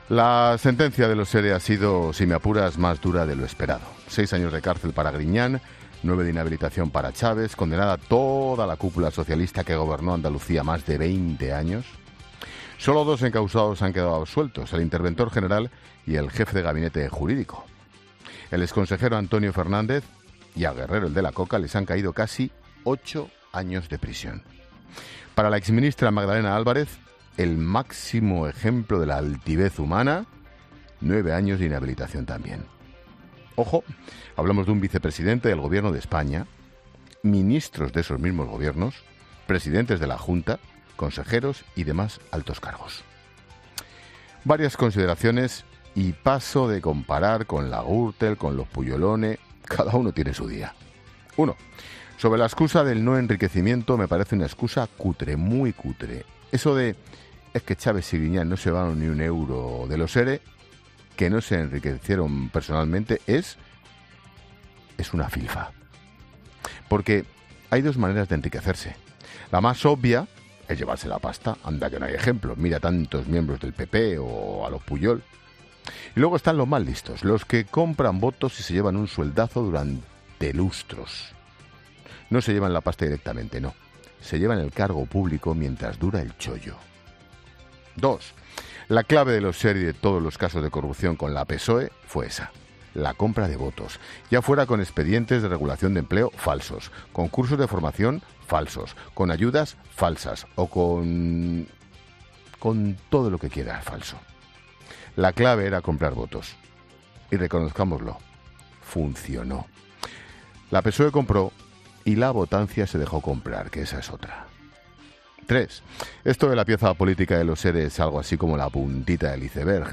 Monólogo de Expósito
El presentador de La Linterna analiza la sentencia de los ERE y las condenas a Manueel Chaves y José Antonio Griñán